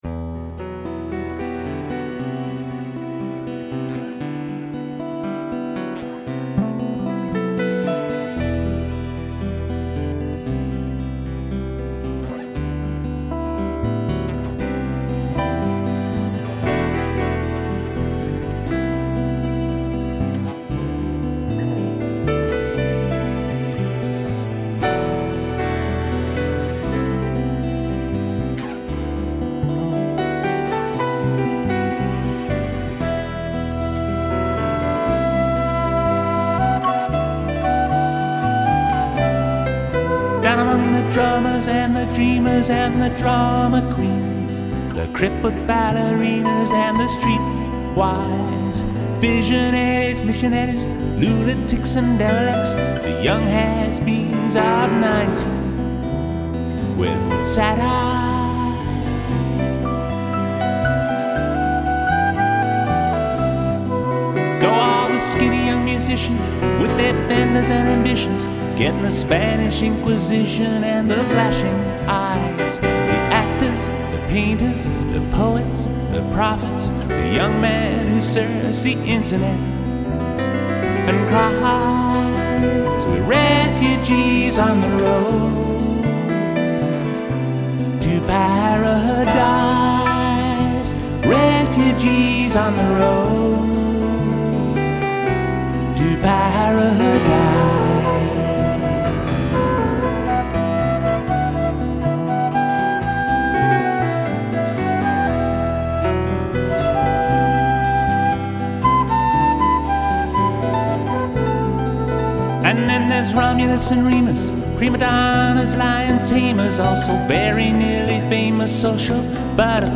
'unplugged' album
laid-back, chilled